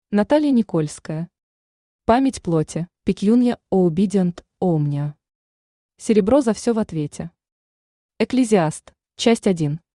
Aудиокнига Память плоти Автор Наталья Никольская Читает аудиокнигу Авточтец ЛитРес.